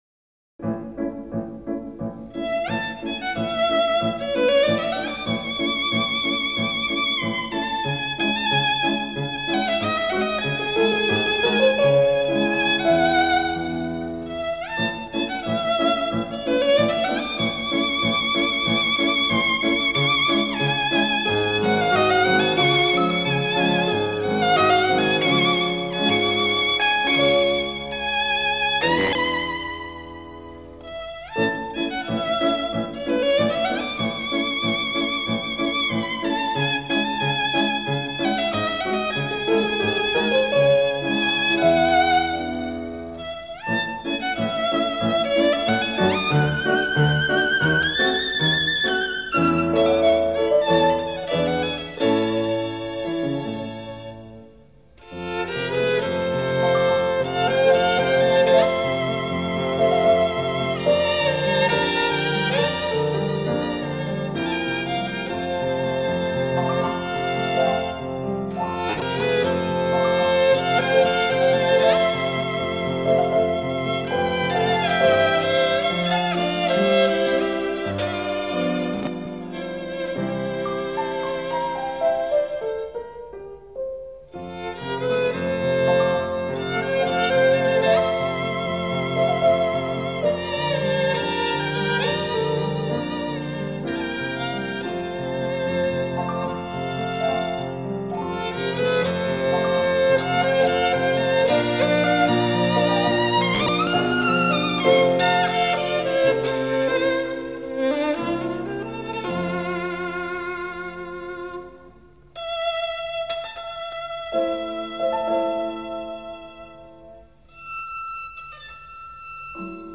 小提琴曲